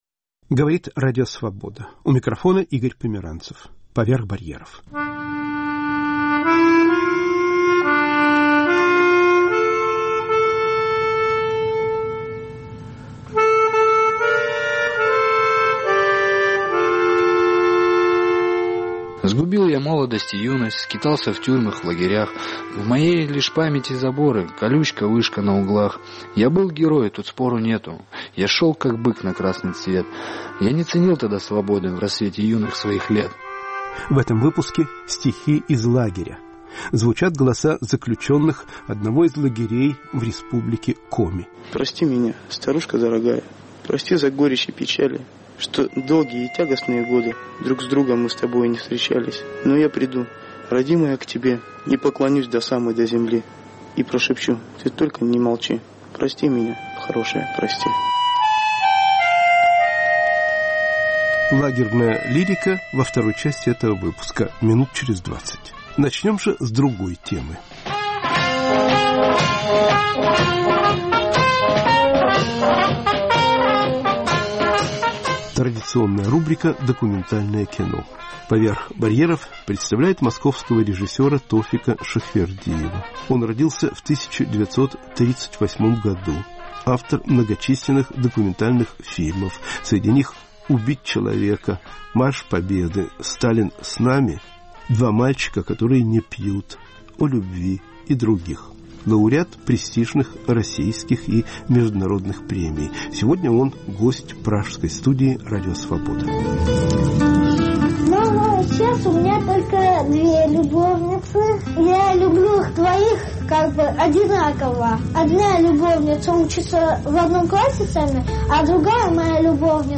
Режиссёр Тофик Шахвердиев и герои его фильмов.*** «Родной язык» с митрополитом Антонием Сурожским (архивная запись).*** Стихи их лагеря.*** Дети из неблагополучных семей.